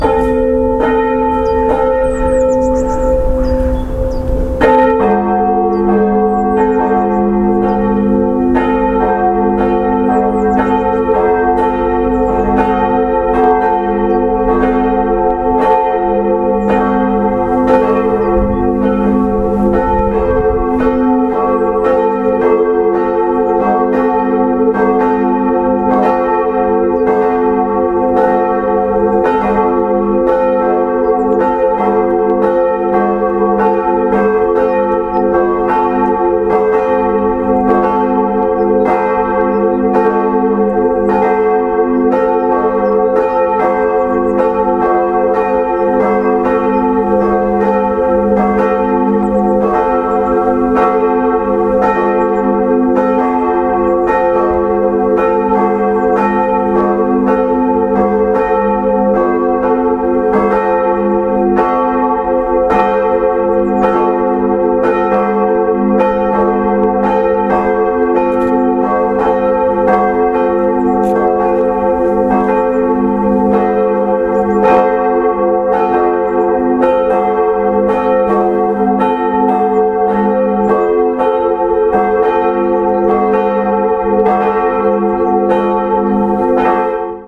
Musik zur Einstimmung
Orgel
eine Choralintonation